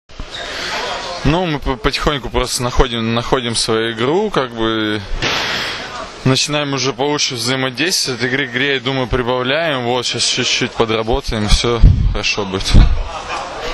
IZJAVA SERGEJA GRANKINA